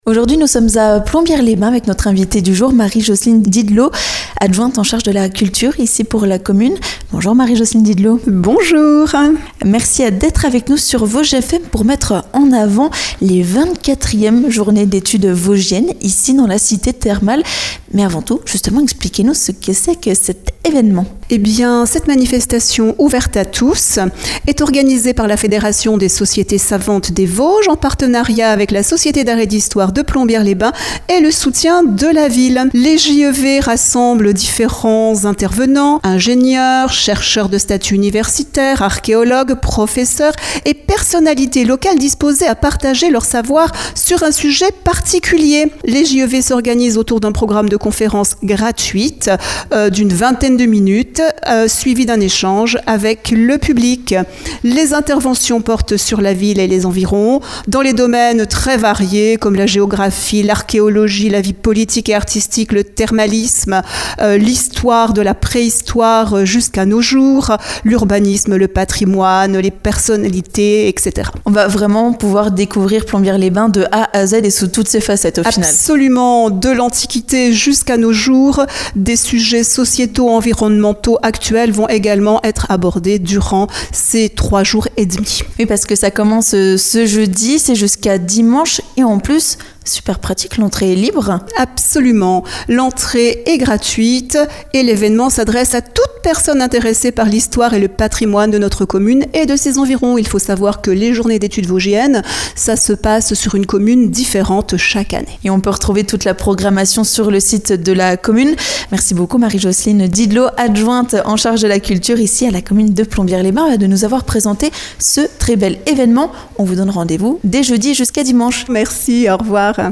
Marie-Jocelyne Didelot, adjointe à la culture à la commune de Plombières-les-Bains, vous invite aux 24èmes Journées d'Etudes Vosgiennes qui commencent ce jeudi et jusqu'à dimanche !